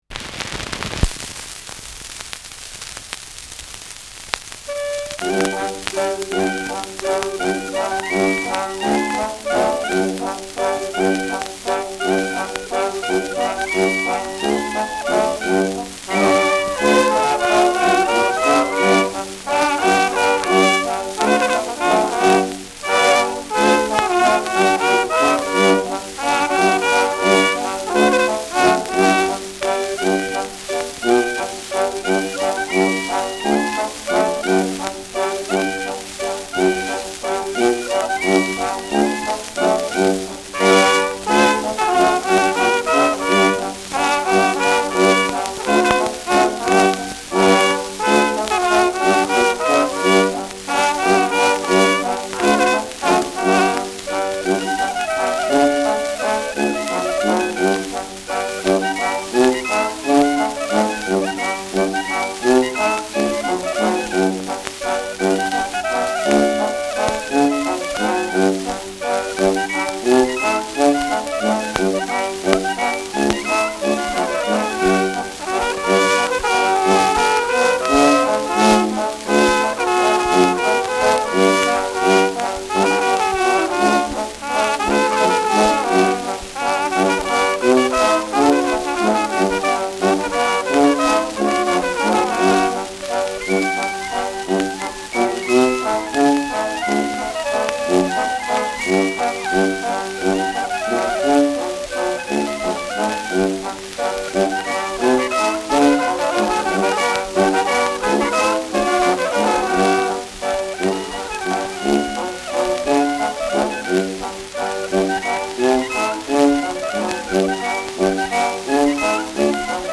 Schellackplatte
Abgespielt : Gelegentlich leichtes Knacken